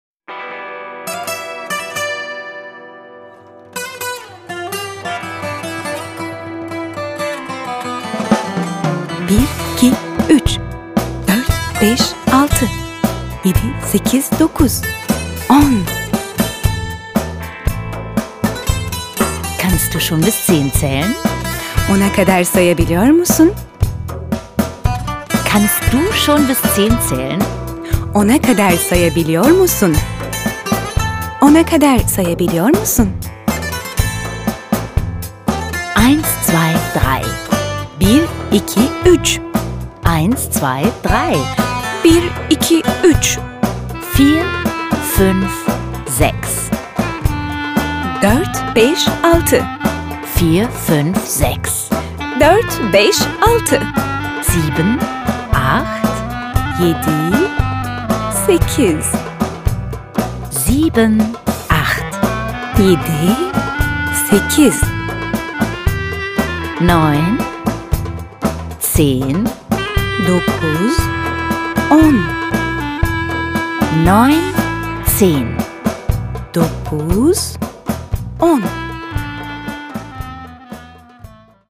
Groovy Basics.Coole Pop & Jazz Grooves / Audio-CD mit Booklet
Süper! Mit den coolen Pop & Jazz Grooves entspannt die wichtigsten Vokabeln, Redewendungen sowie smarte Sprüche für Anfänger lernen.